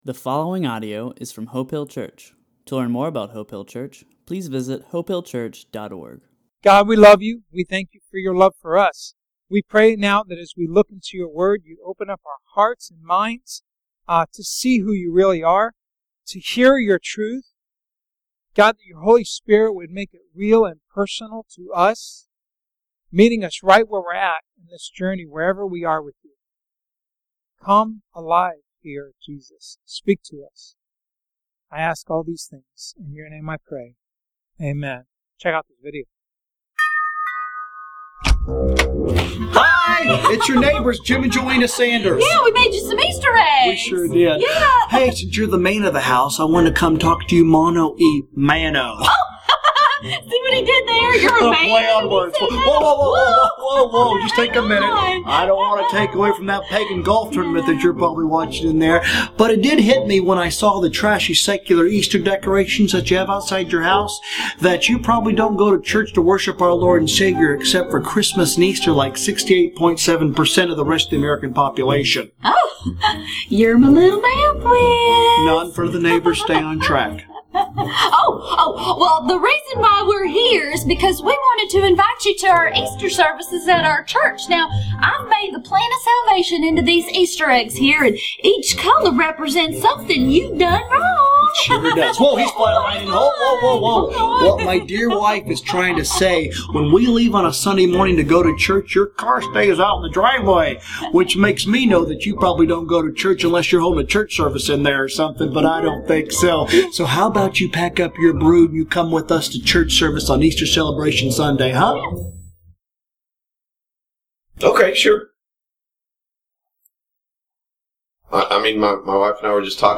A message from the series "The Bible."